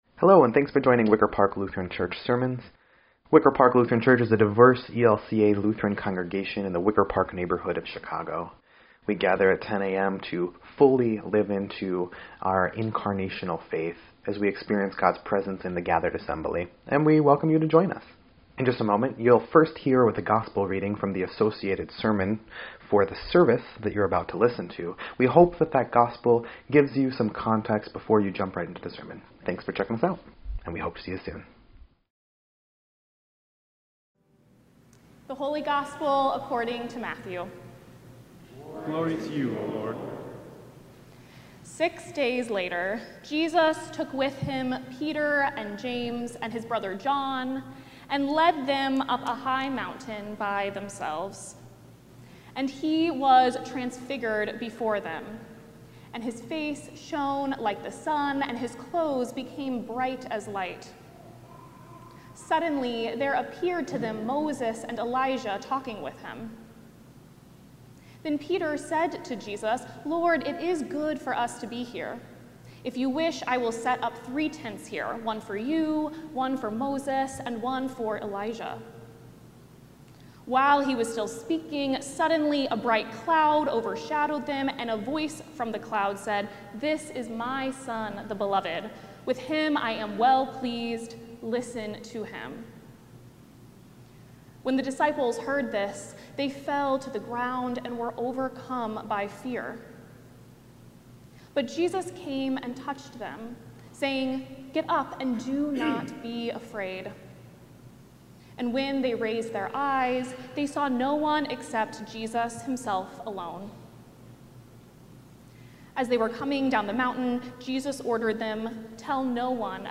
2.15.26-Sermon_EDIT.mp3